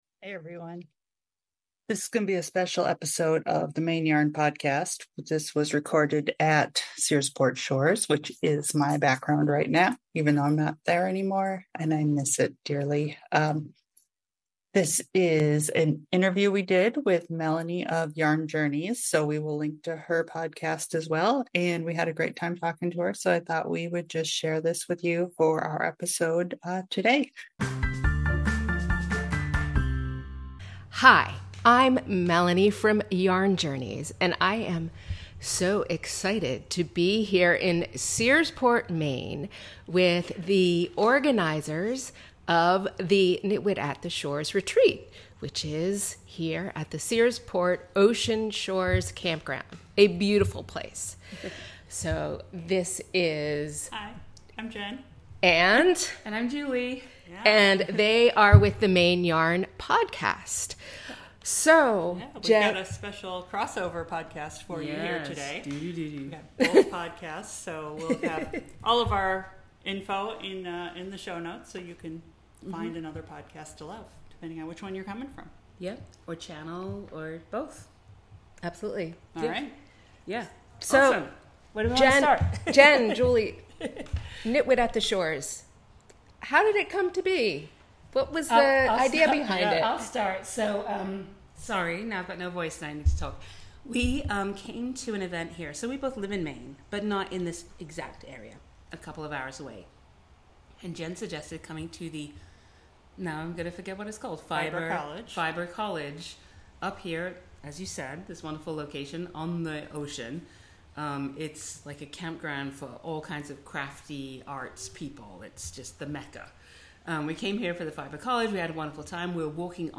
Maine Yarn Podcast A discussion
at our knitting retreat